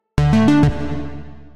効果音のフリー素材です。
効果音3